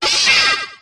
perrserker_ambient.ogg